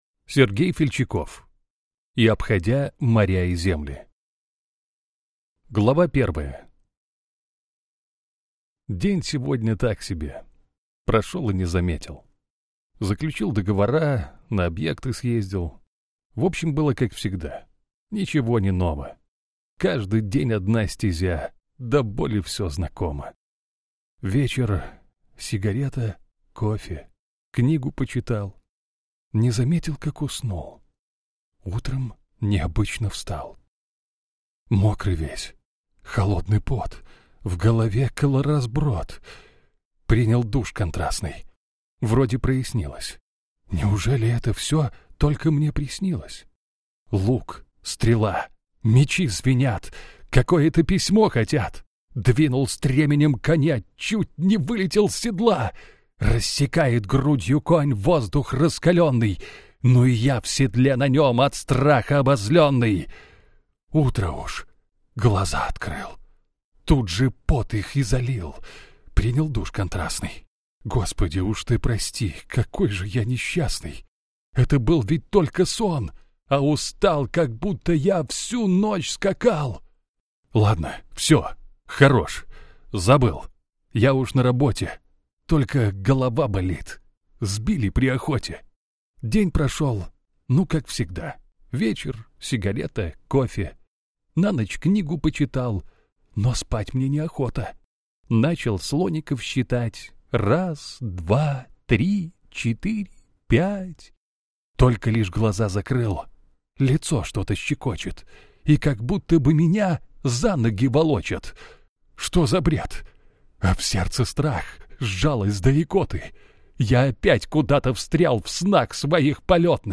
Аудиокнига И обходя моря и земли | Библиотека аудиокниг